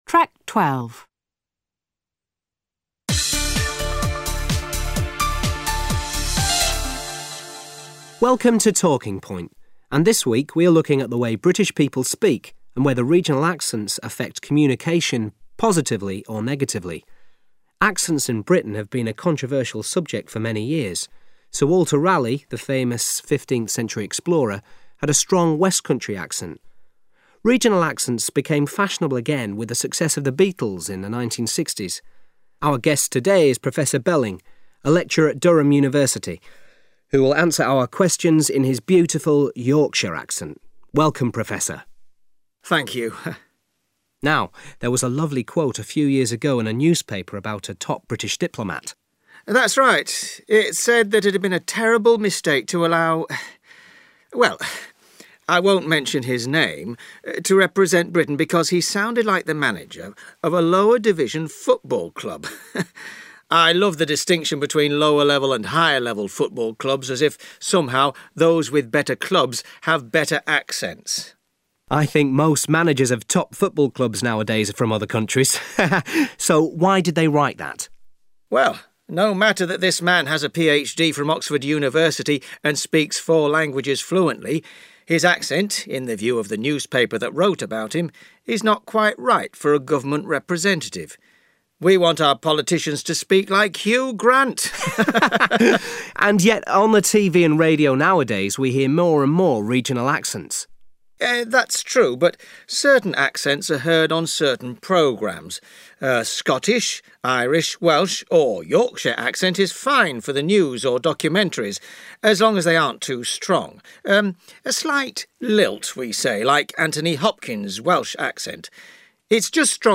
LP5 - Regional accents audio.mp3